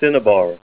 Say CINNABAR Help on Synonym: Synonym: Cinabre   ICSD 70054   PDF 42-1408   Zinnober